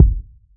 • Warm Sounding Urban Kick G Key 462.wav
Royality free bass drum sample tuned to the G note. Loudest frequency: 101Hz
warm-sounding-urban-kick-g-key-462-jV9.wav